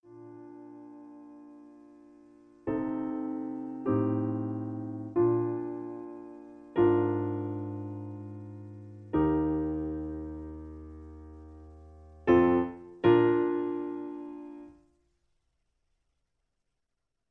Original Key (B flat). Piano Accompaniment